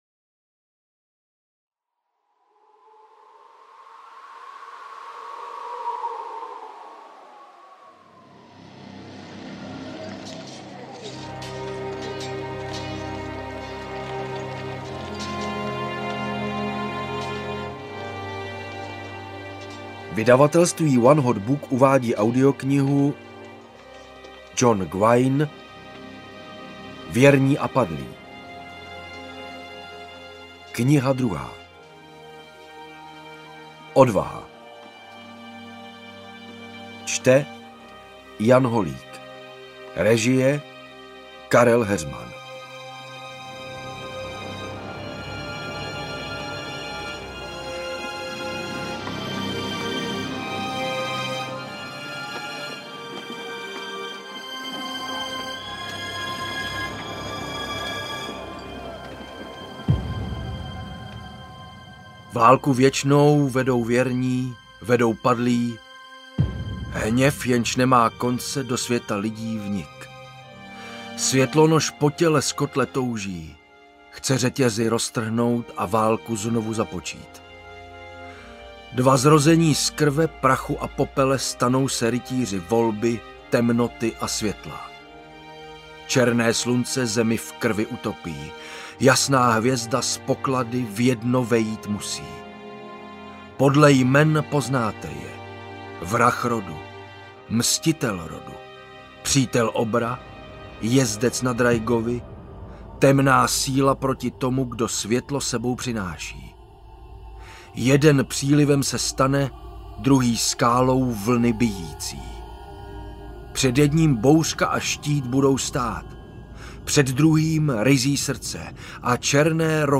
AudioKniha ke stažení, 120 x mp3, délka 26 hod. 11 min., velikost 1408,0 MB, česky